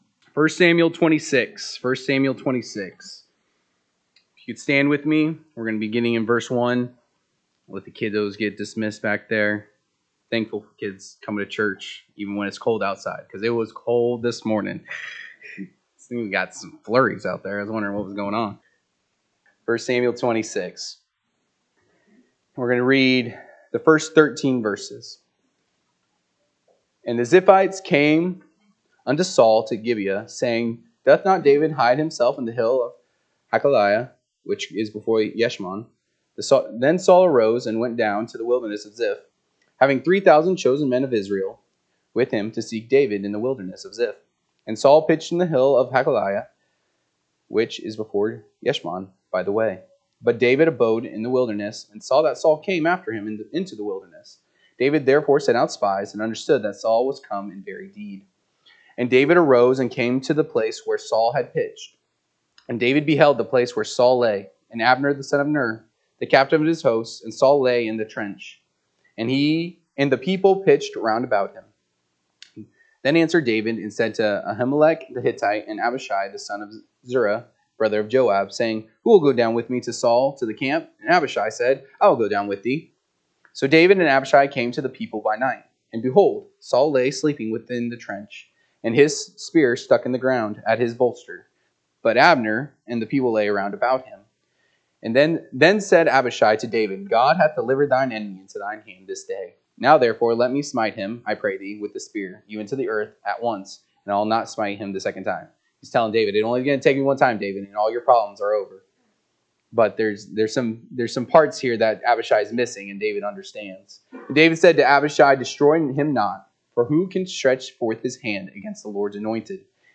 November 30, 2025 am Service 1 Samuel 26:1-13 (KJB) 26 And the Ziphites came unto Saul to Gibeah, saying, Doth not David hide himself in the hill of Hachilah, which is before Jeshimon? 2 …
Sunday AM Message